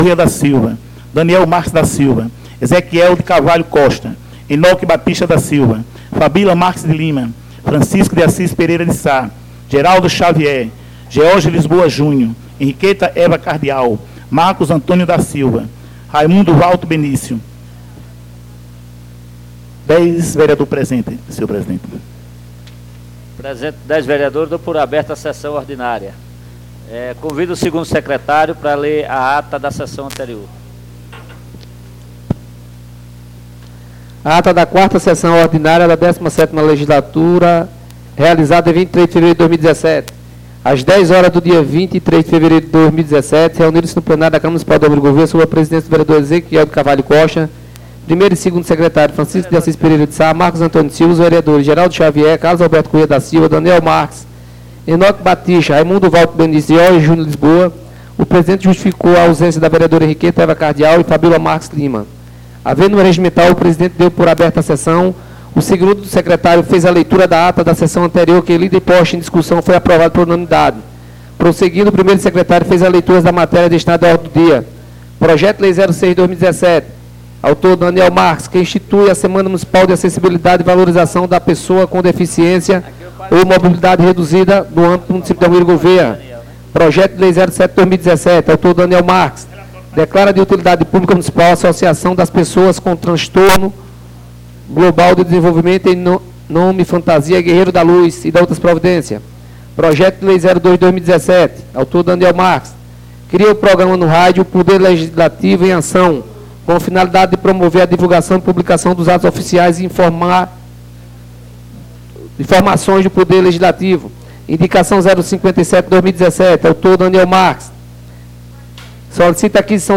Nº 05/2017 DATA 09/03/2017 TIPO Ordinárias DESCRIÇÃO 5ª Sessão Ordinária da 17ª (Décima Sétima) Legislatura (2017 - 2020) no dia 09 de março de 2017.